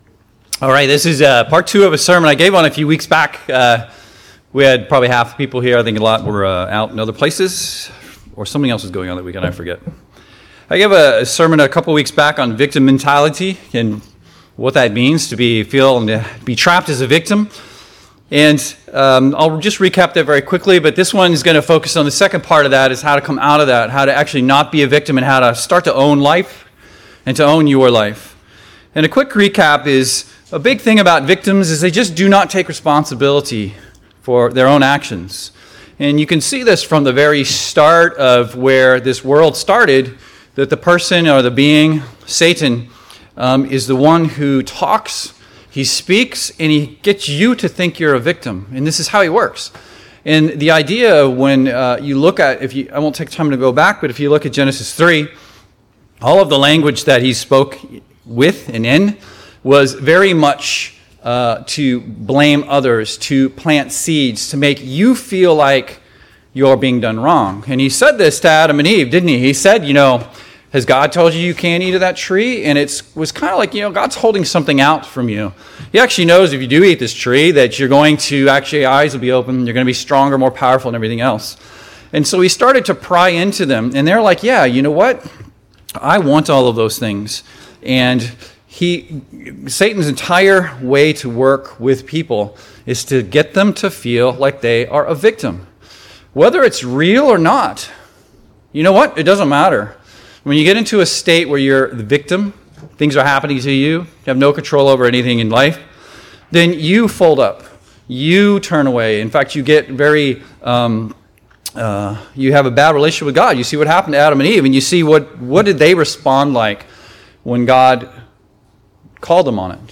This is part two of a message on victim mentality. How can we go from being victims in our lives to owning and taking responsibility for our life start to finish. We discuss the latest scientific research that backs up the bible and go through practical example of how to completely change your life and own it.
Given in Seattle, WA